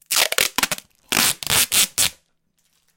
tape_roll2.wav